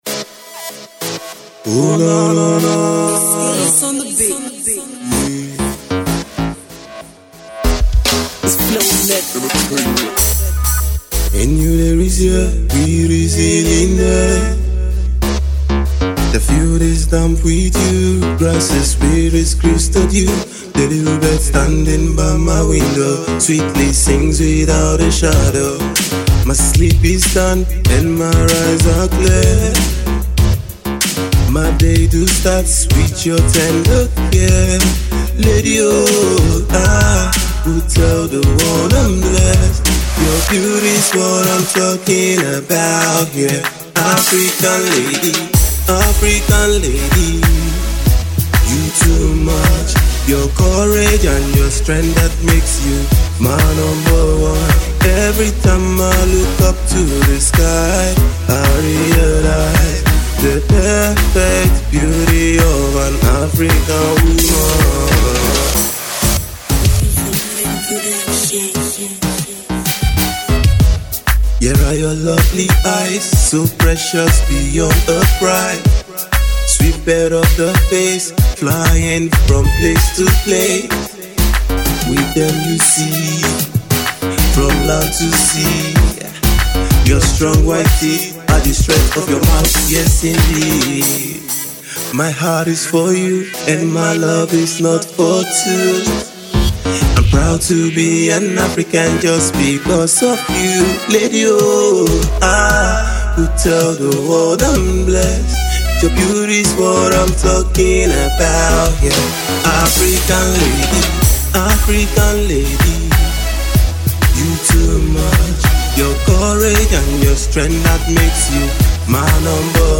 Its a Lovely ballad, which was smoothly performed by him